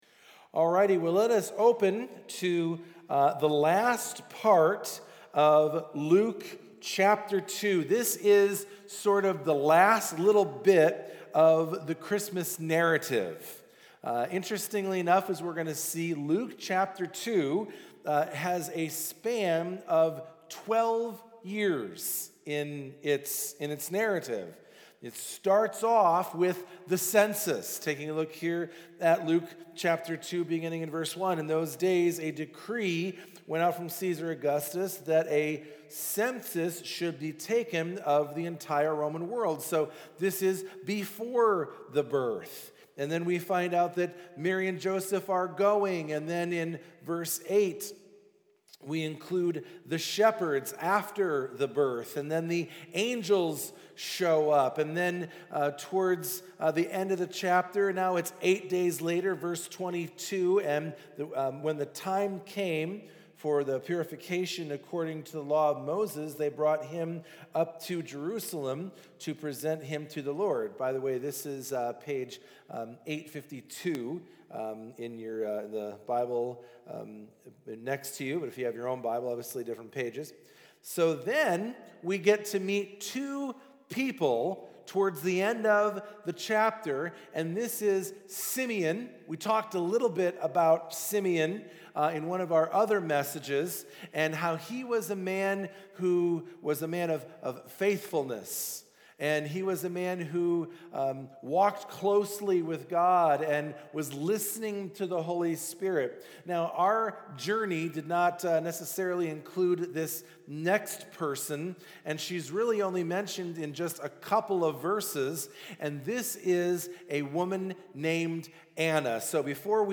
This Weeks Sermon